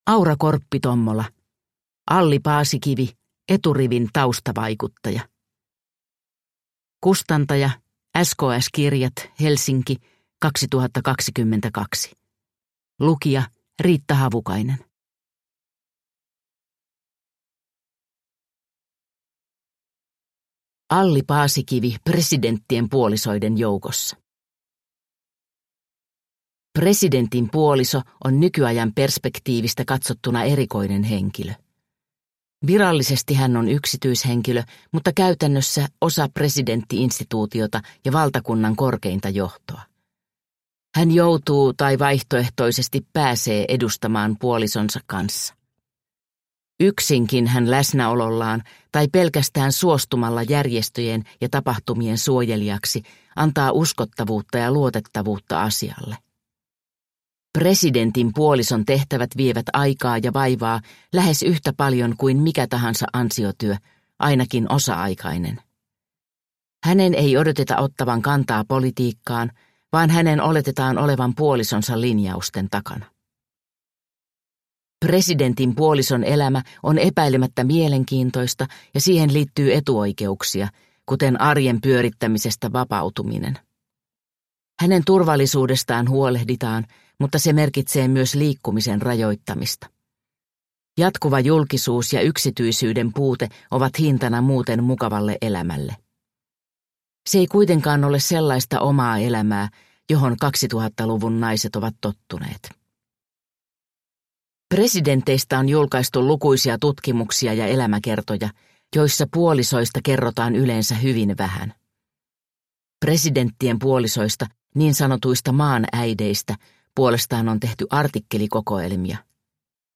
Alli Paasikivi – Ljudbok – Laddas ner